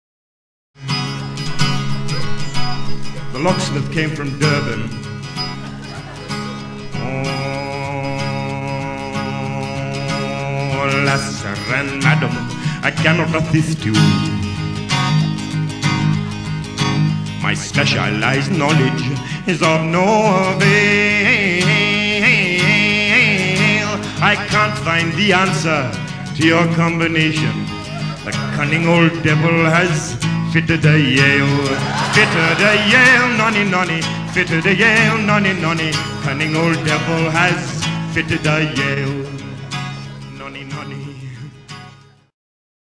MONO Soundtrack